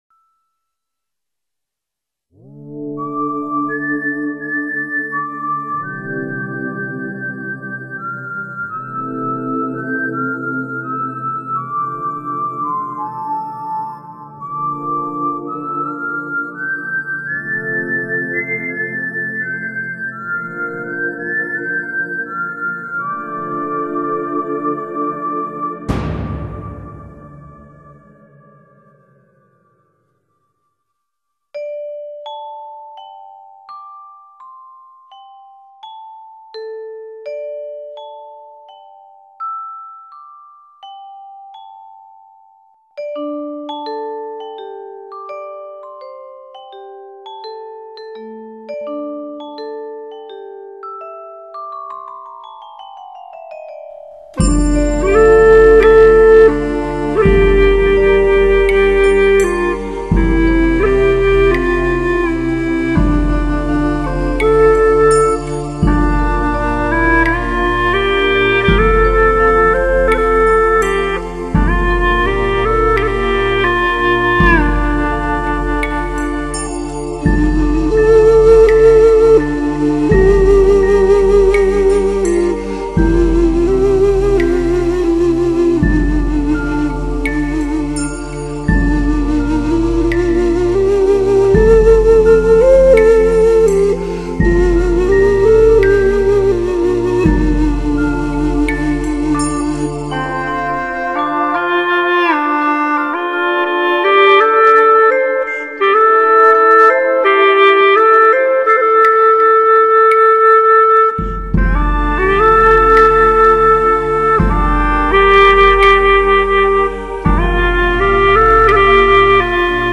吹管